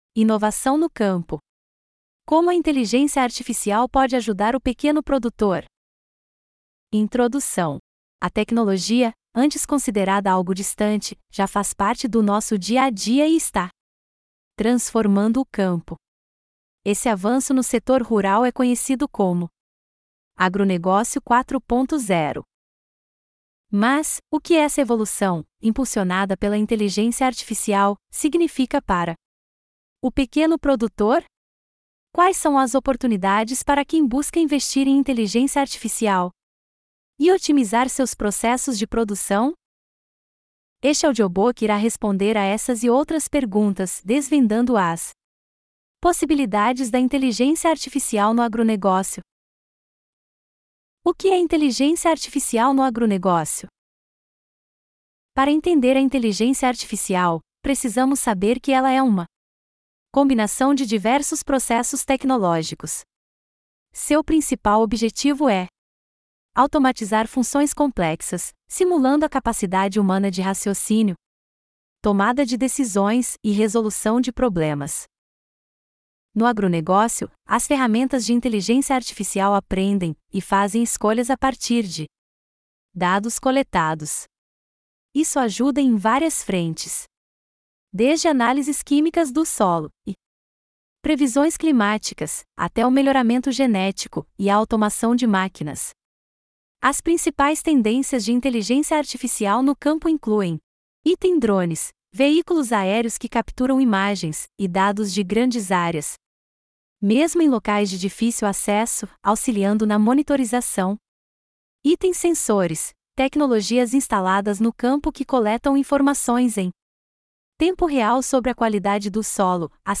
Inovação no Campo: Como a IA pode ajudar o pequeno produtor em audiobook. - Sebrae
audiobook-inovação-no-campo.mp3